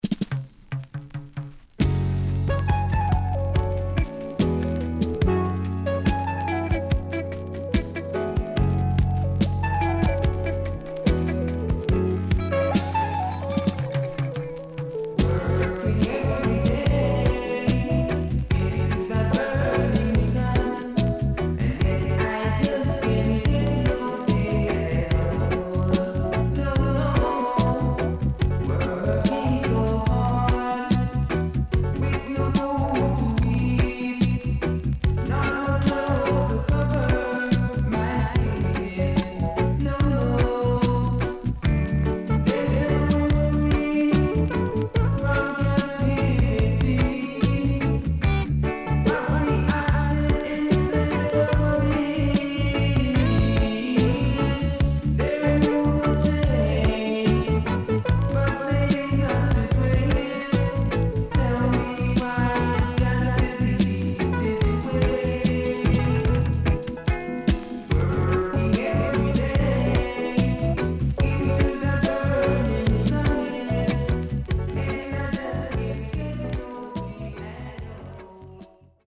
Early 80's Deep & Heavy Vocal.全曲Version付きのShowcase Style
SIDE A:所々チリノイズがあり、少しプチノイズ入ります。